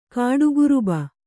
♪ kāḍuguruba